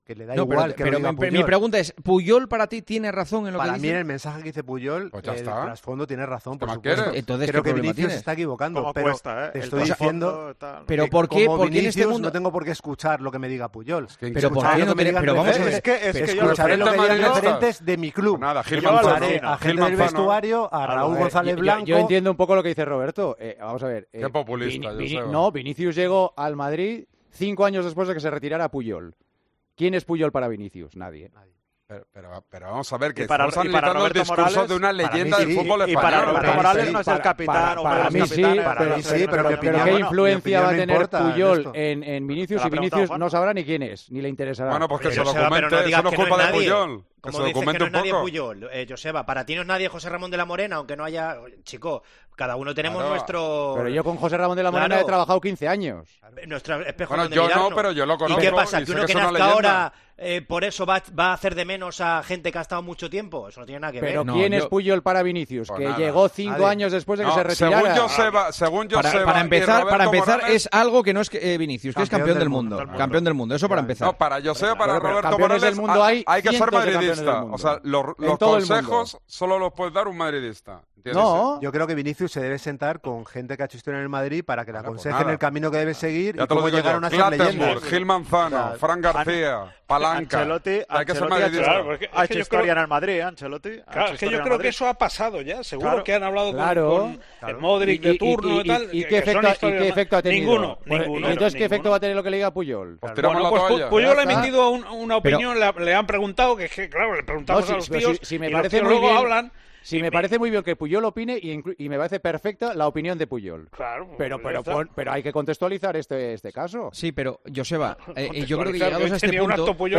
AUDIO: El director de El Partidazo de COPE habló de las palabras de Carles Puyol sobre Vinicius y su polémica actitud en el campo.